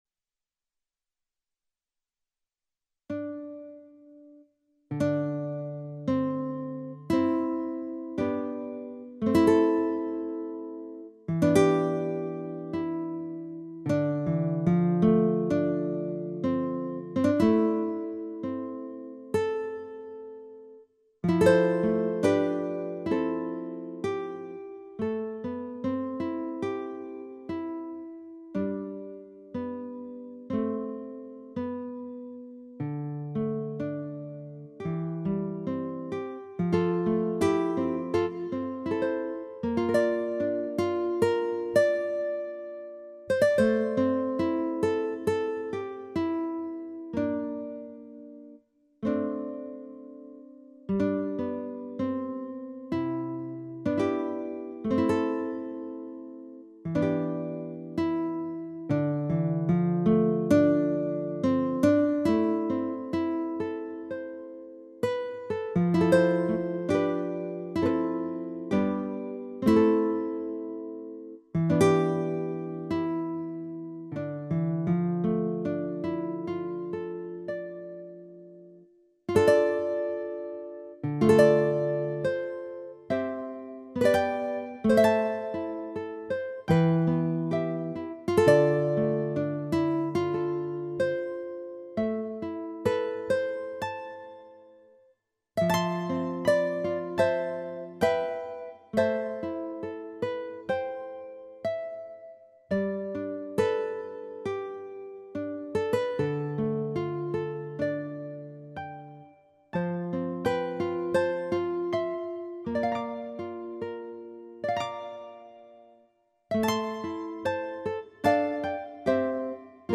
The first project is simply instrumental Catholic/Christian standards as music for meditation to use during Lent and Holy Week.